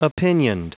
Prononciation du mot opinioned en anglais (fichier audio)
Prononciation du mot : opinioned